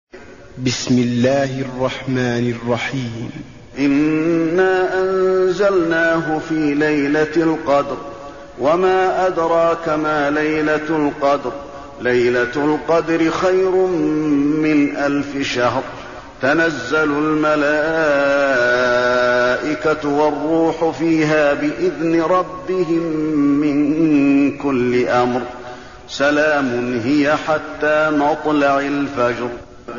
المكان: المسجد النبوي القدر